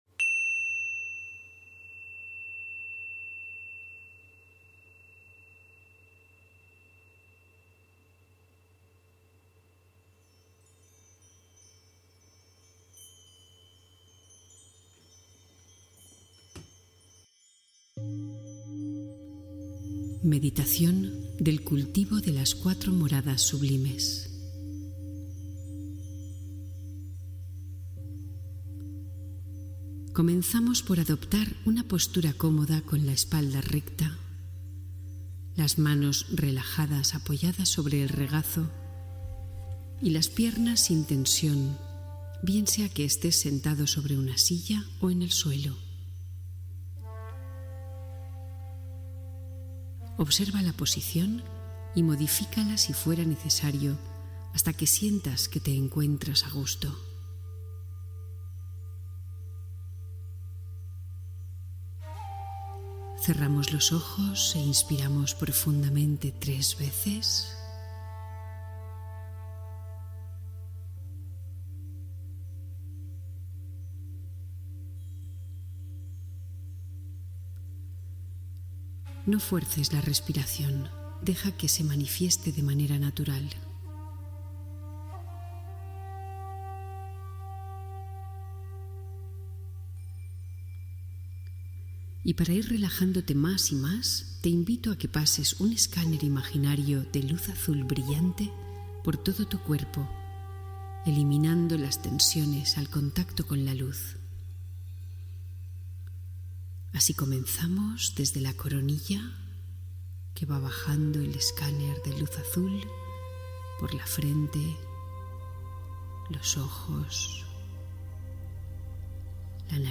Las cuatro moradas sublimes: meditación guiada para el amor y la compasión